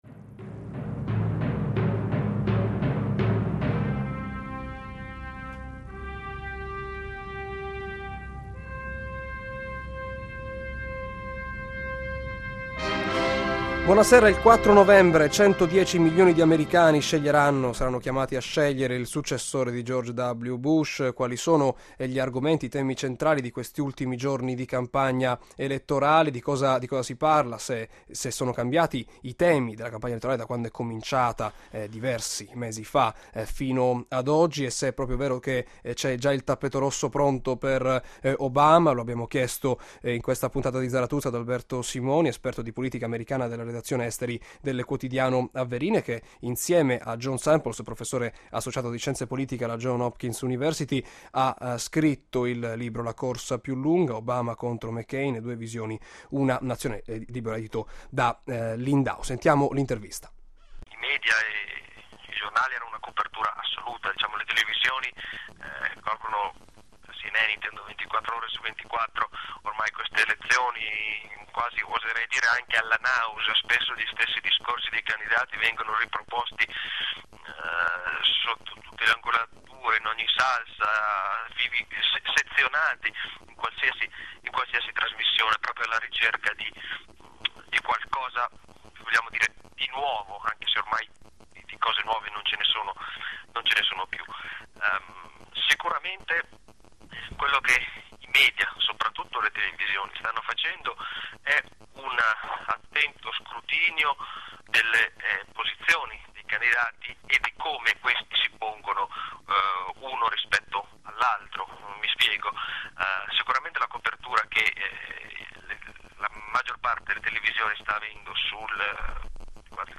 Ascolta la puntata di Zarathustra andata in onda sabato 1 novembre, alle 18,05, su Radio Italia anni '60 - Emilia Romagna.